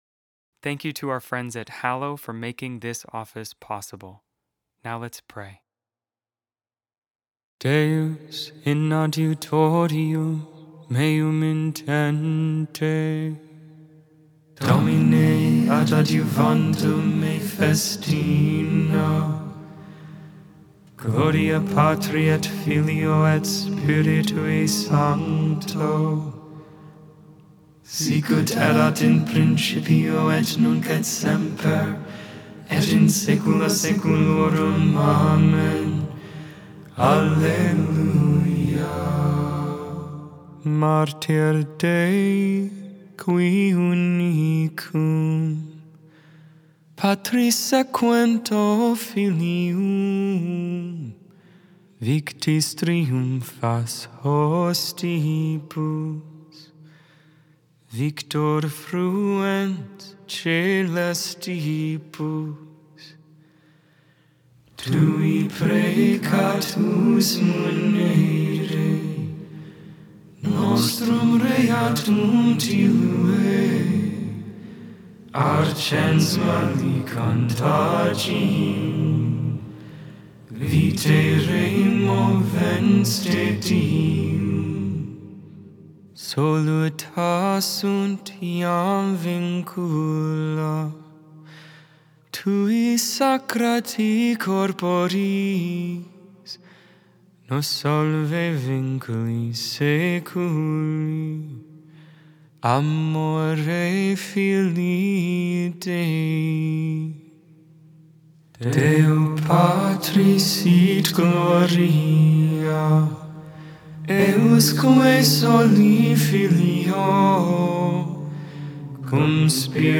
Lauds, Morning Prayer for the 32nd Wednesday in Ordinary Time, November 12, 2025.Memorial of St. Josephat, Bishop and Martyr Made without AI. 100% human vocals, 100% real prayer.